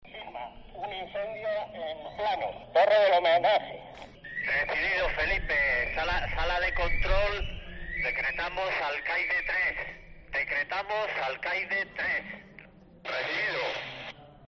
SIMULACRO SONIDO AMBIENTE